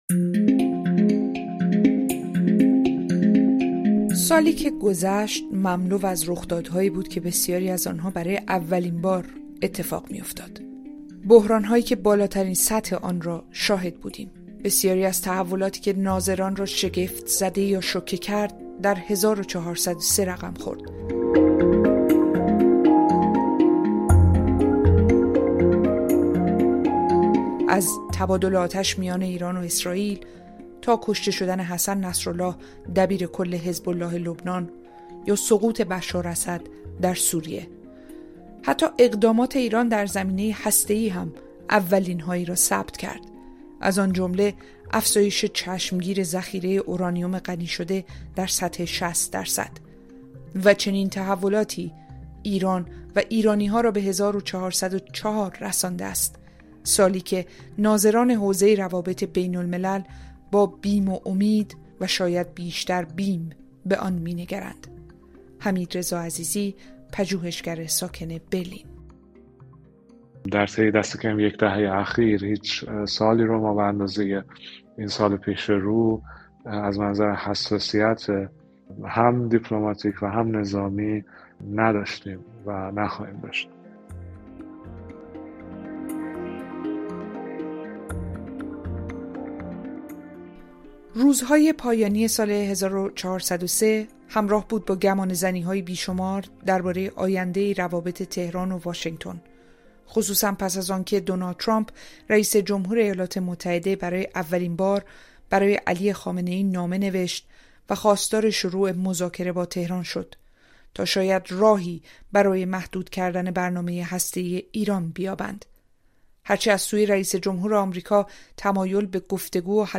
در گفت‌وگو با کارشناسان حوزه سیاست خارجی به بررسی شرایط موجود و چشم‌انداز وضعیت ایران در سطح بین‌الملل پرداخته‌ایم. آیا ایران به سمت مذاکره حرکت می‌کند یا تقابل؟